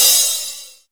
percussion 25.wav